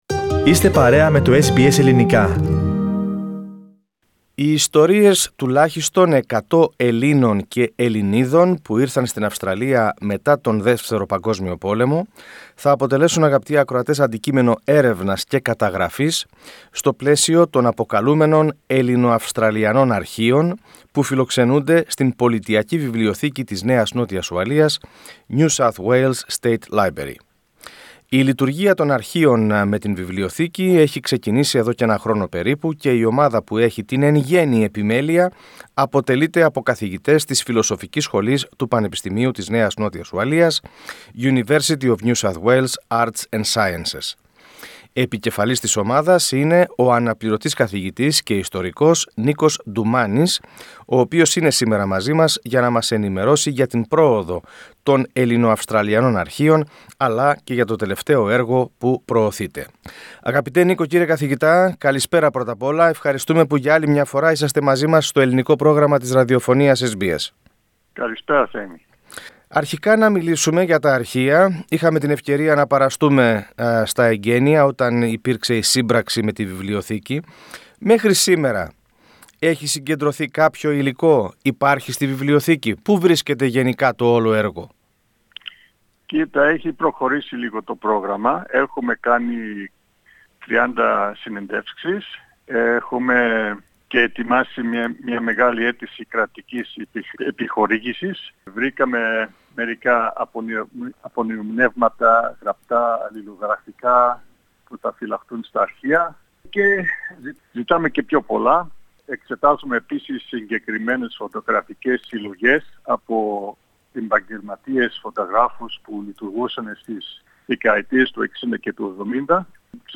Press Play to hear the full interview in Greek.